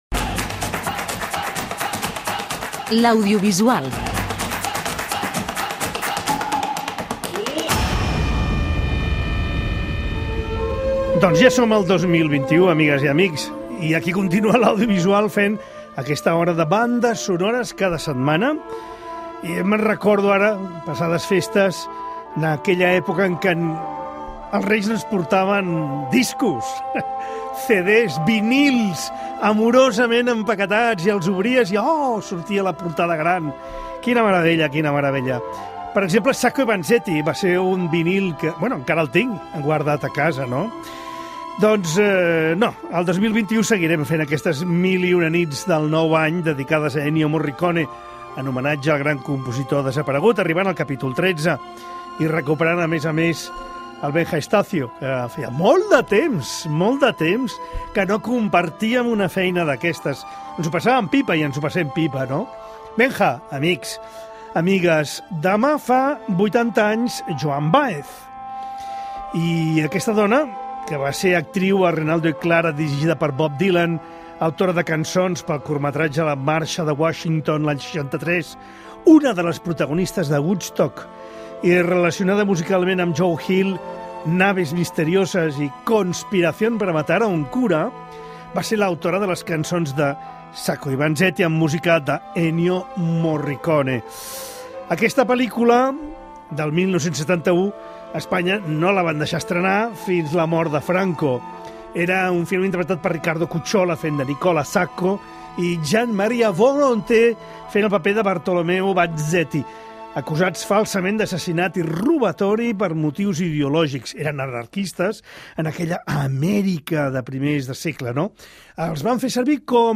temes de la banda sonora